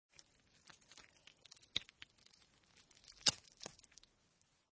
Descarga de Sonidos mp3 Gratis: miedo 15.